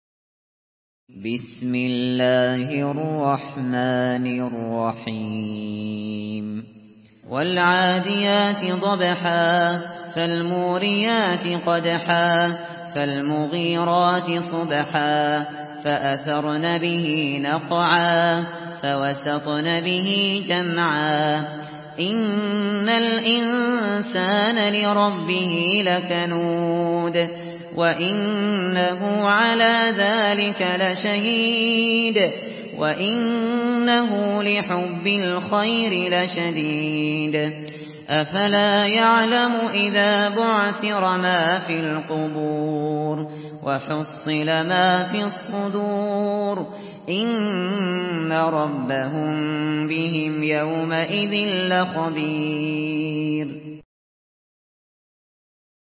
Surah Al-Adiyat MP3 by Abu Bakr Al Shatri in Hafs An Asim narration.
Murattal Hafs An Asim